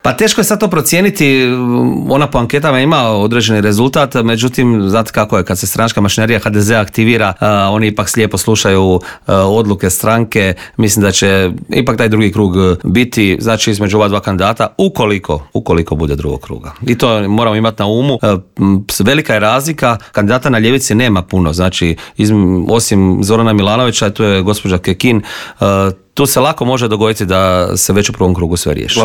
O ovogodišnjoj obljetnici i brojnim drugim političkim aktualnostima u Intervjuu Media servisa razgovarali smo s predsjednikom Hrvatskih suverenista Marijanom Pavličekom koji je poručio: "Čovjek se naježi kada vidi sve te mlade ljude koji idu prema Vukovaru."